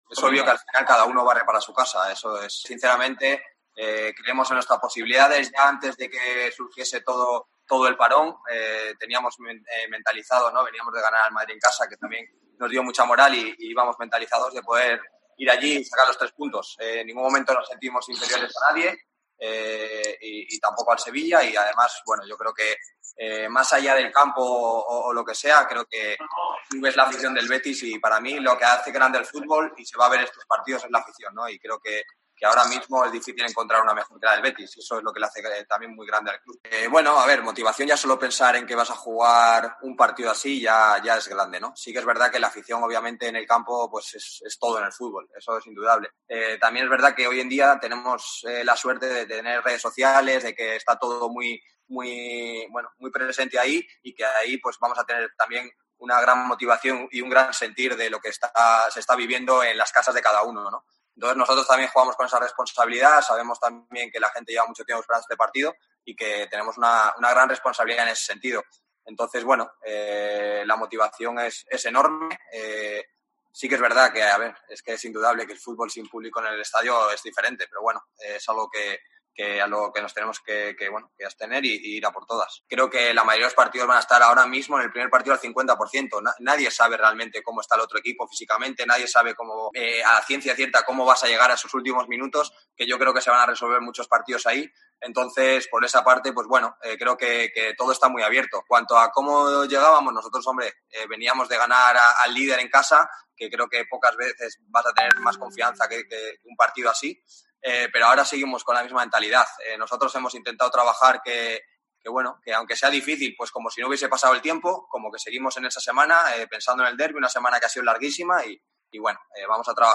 CANALES, EN LA RUEDA DE PRENSA DE ESTE MARTES